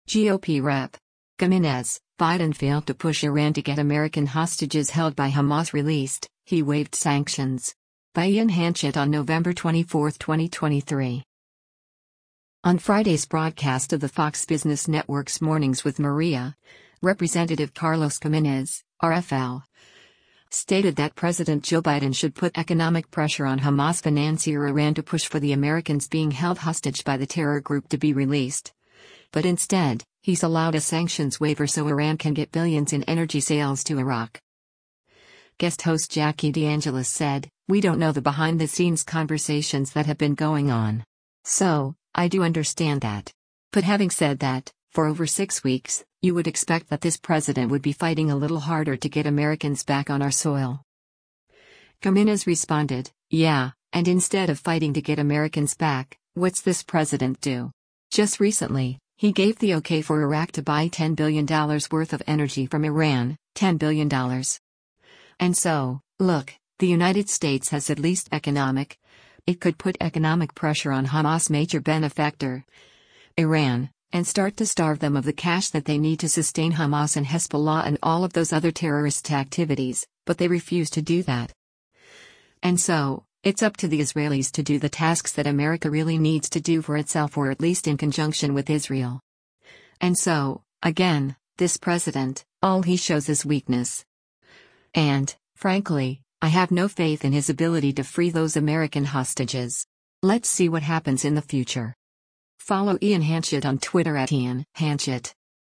On Friday’s broadcast of the Fox Business Network’s “Mornings with Maria,” Rep. Carlos Gimenez (R-FL) stated that President Joe Biden should put economic pressure on Hamas financier Iran to push for the Americans being held hostage by the terror group to be released, but instead, he’s allowed a sanctions waiver so Iran can get billions in energy sales to Iraq.